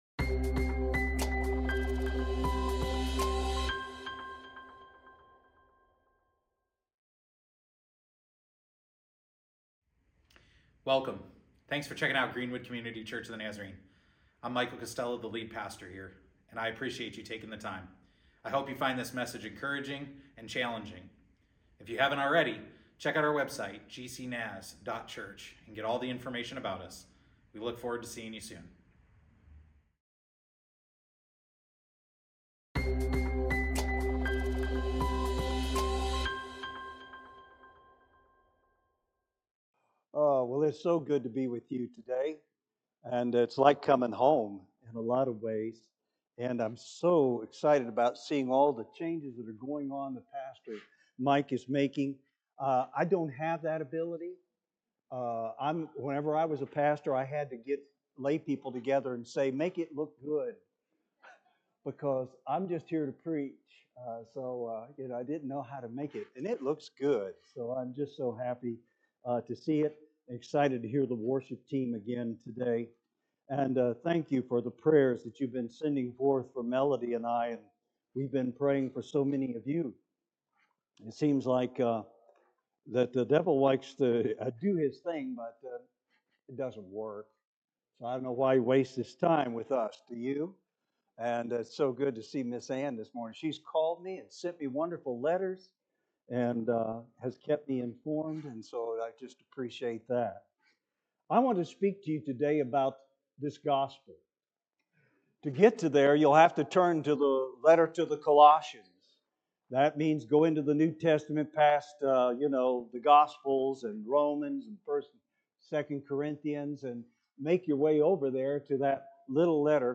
Special Guest Preacher